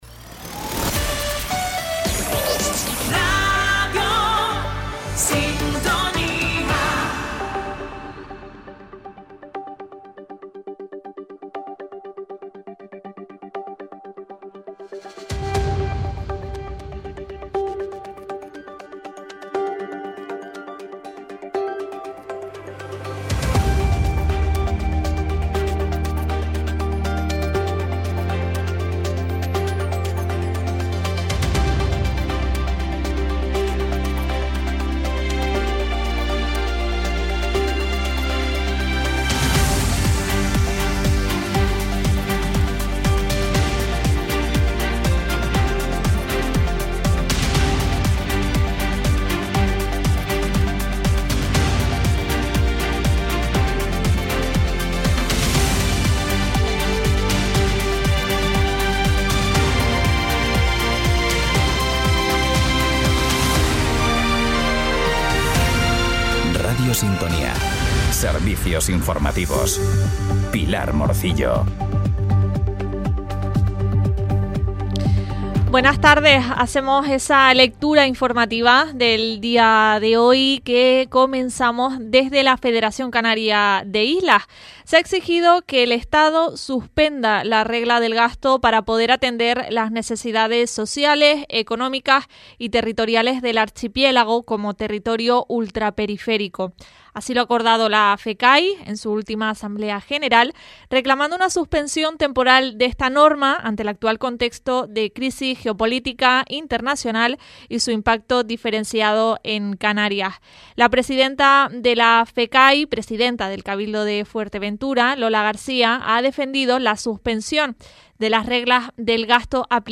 Informativos en Radio Sintonía - Radio Sintonía